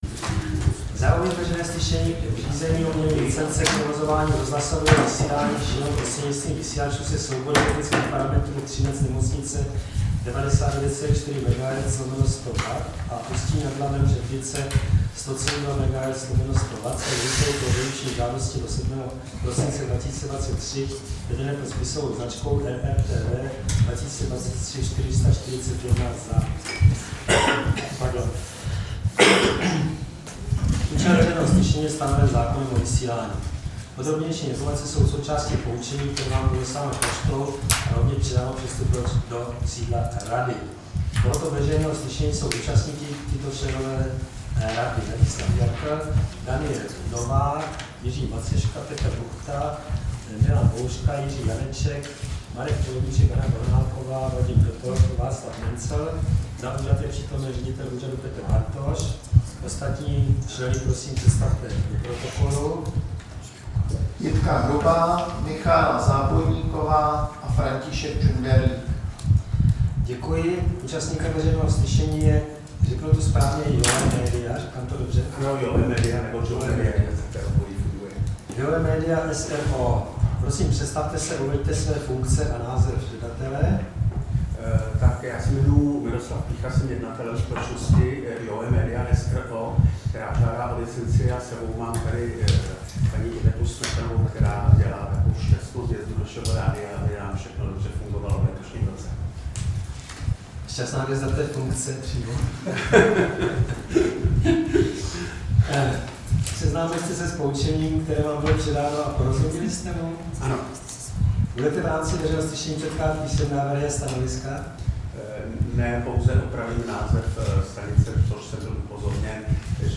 Veřejné slyšení v řízení o udělení licence k provozování rozhlasového vysílání šířeného prostřednictvím vysílačů se soubory technických parametrů Třinec-nemocnice 99,4 MHz/100 W a Ústí nad Labem-Předlice 100,2 MHz/100 W
Místem konání veřejného slyšení je sídlo Rady pro rozhlasové a televizní vysílání, Škrétova 44/6, 120 00 Praha 2.